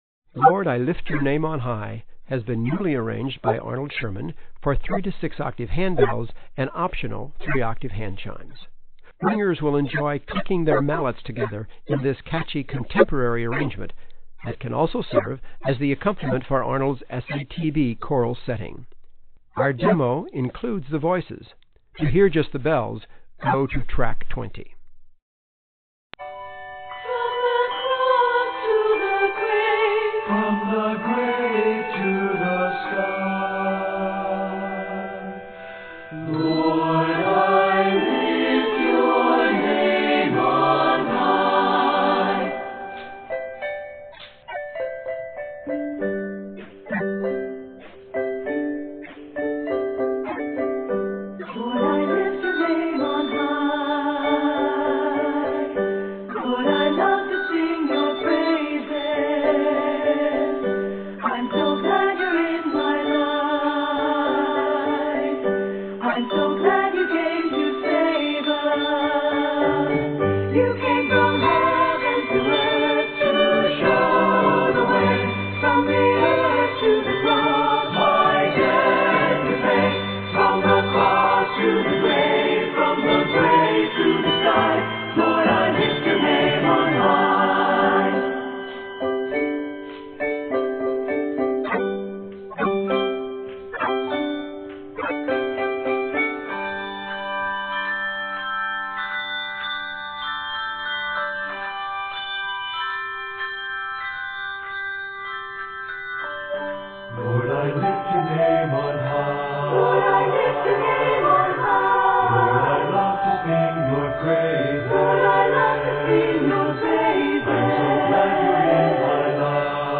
ring-and-sing setting
praise and worship song